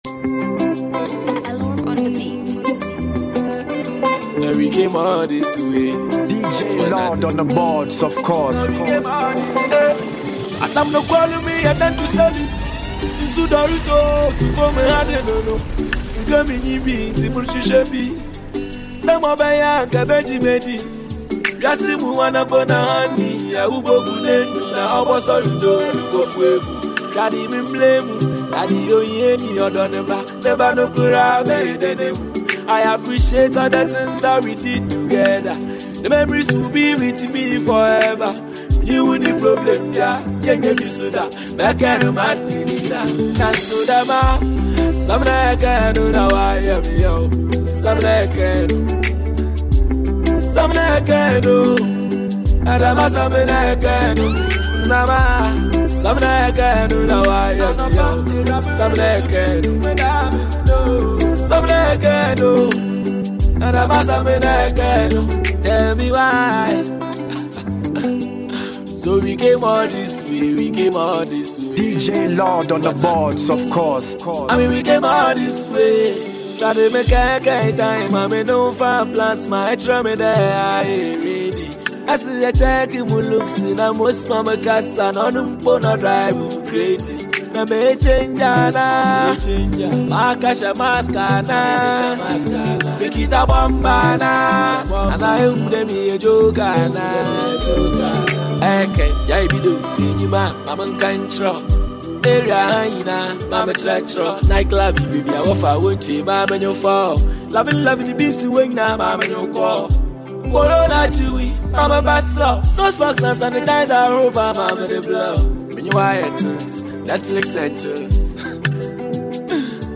expertly blends both old and new classics